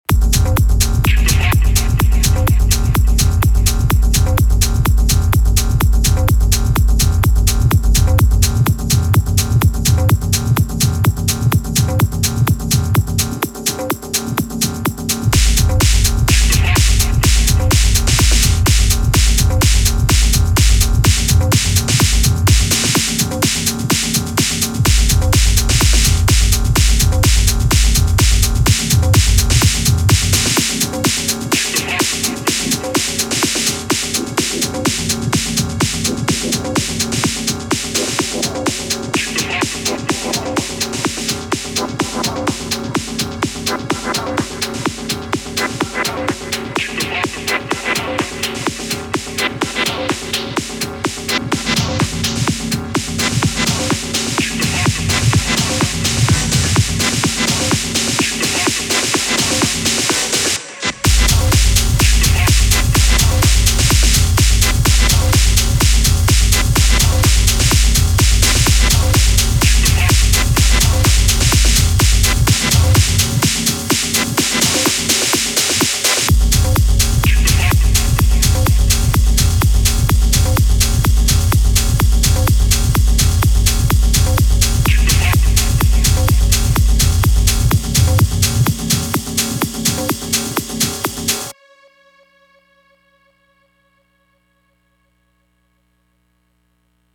Sorry people, these are pushing it into the harder house side but I’m thinking a house DJ might play them :slight_smile:
For the second one, I sampled a sequence from my DT2 and both include sampled records from my collection.